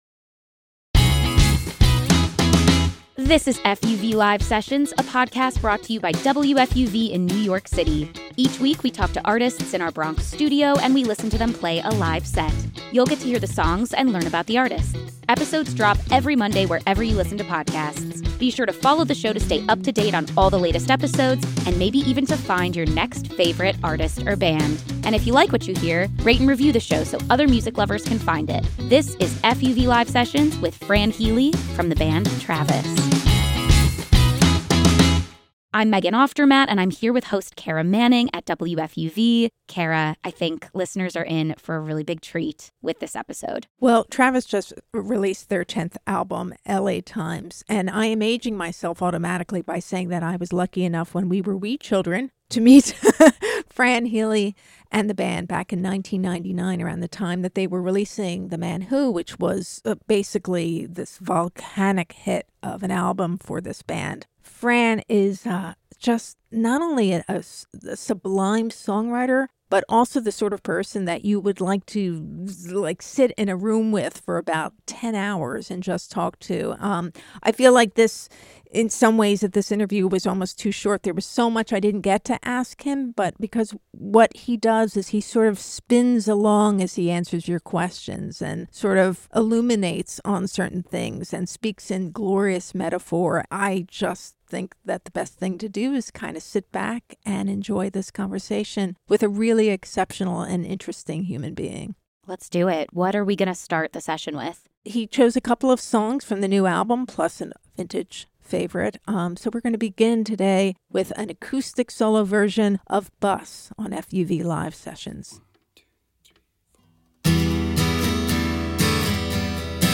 plays an acoustic set
Studio A